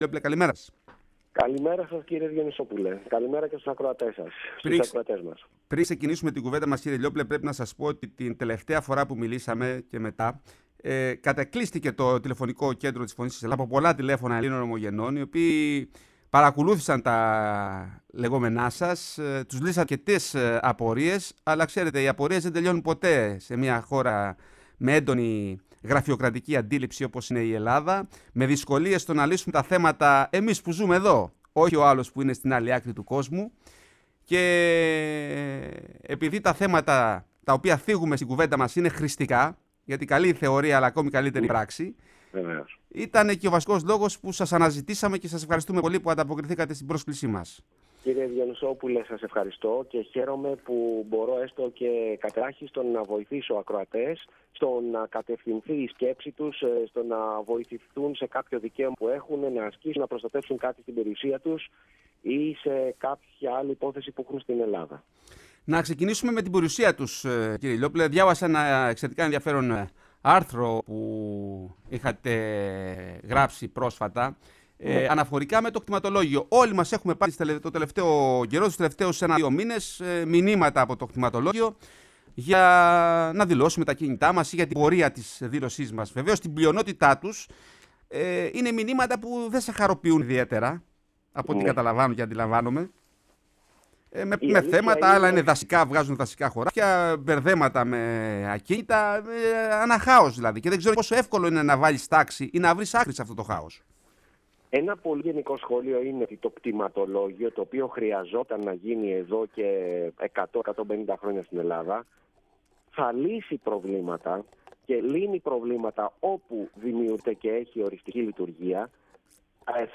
Μιλώντας στην εκπομπή «Η Ελλάδα στον κόσμο»